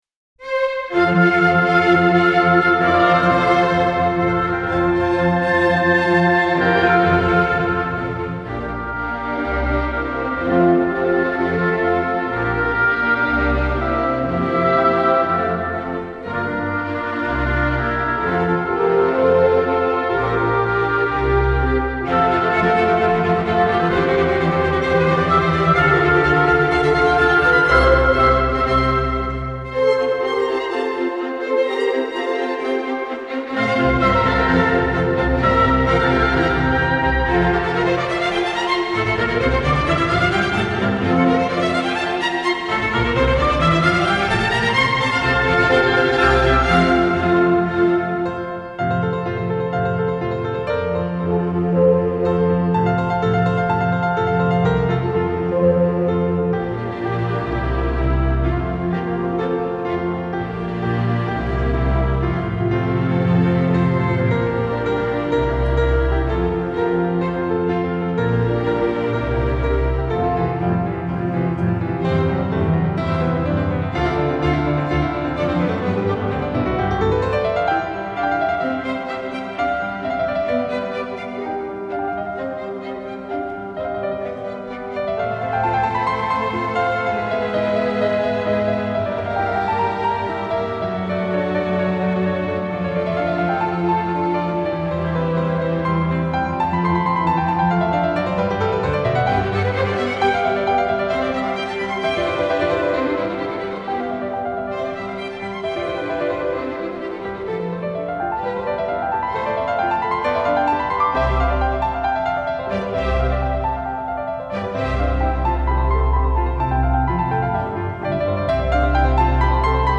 Piano Solo Oboes French Horns Violin 1 Violin 2 Viola Bass
Style: Classical